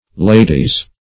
Lady \La"dy\ (l[=a]"d[y^]), n.; pl. Ladies (l[=a]"d[i^]z).